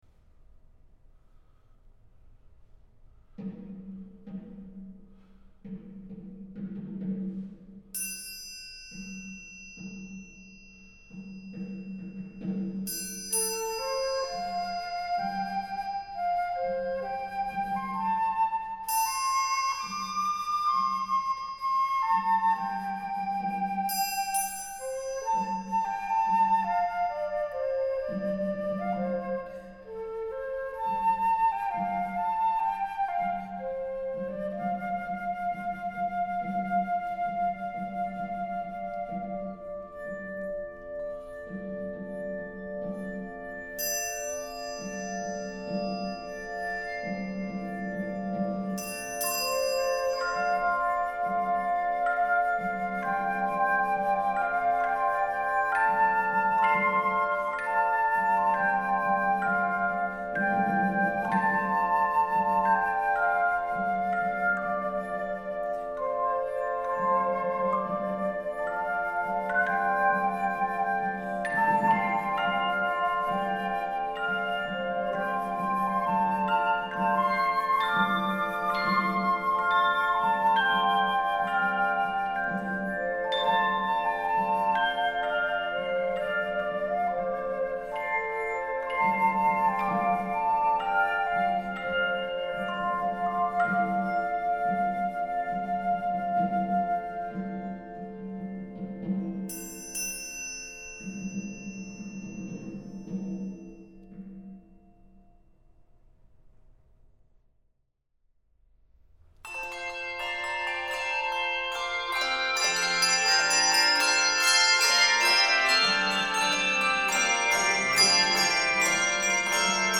Composer: Traditional Scottish Tune
Voicing: 3, 4 or 5